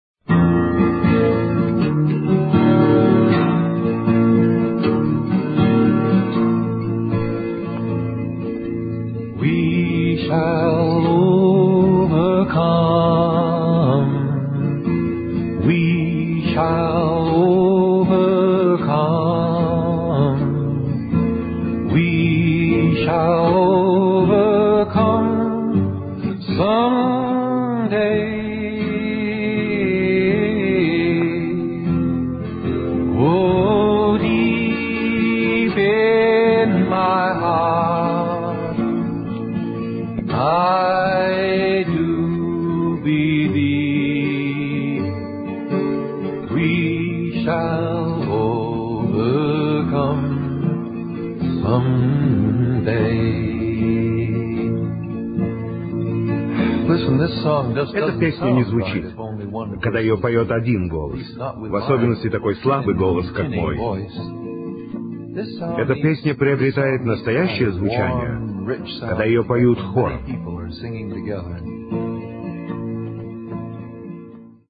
Вот как начиналась та самая звуковая страничка «Кругозора»: